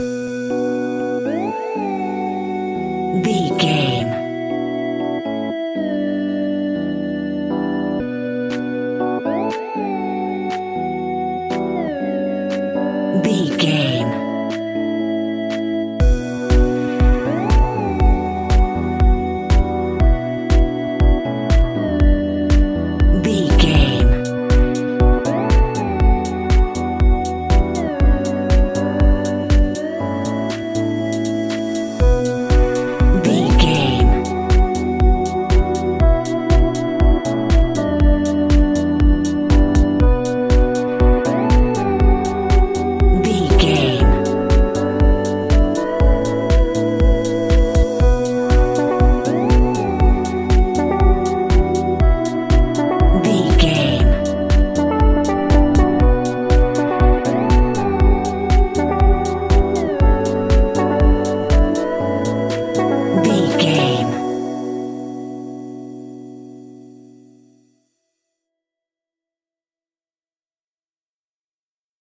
Aeolian/Minor
funky
groovy
uplifting
driving
energetic
drum machine
synthesiser
electric piano
house
electro house
funky house
synth leads
synth bass